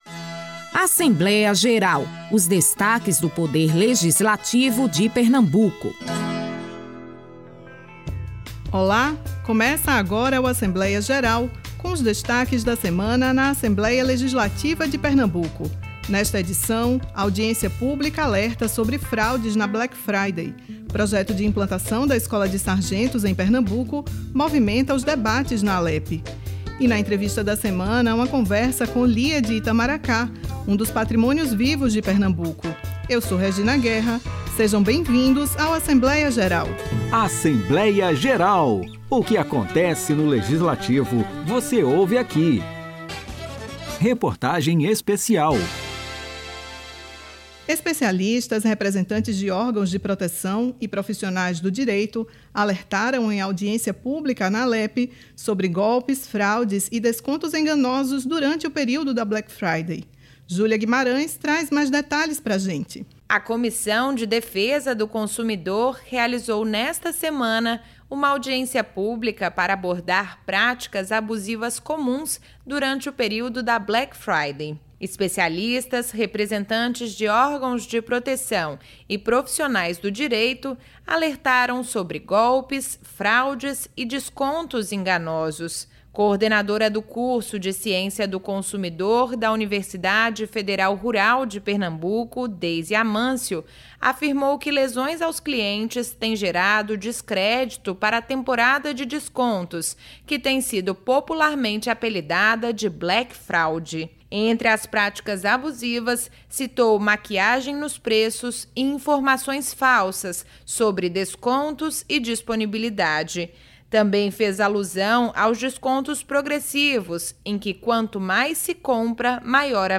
Nesta edição, você pode conferir as audiências públicas sobre fraudes na Black Friday e o projeto de implantação da Escola de Sargentos. E, na entrevista da semana, uma conversa com Lia de Itamaracá, um dos patrimônios vivos do nosso estado.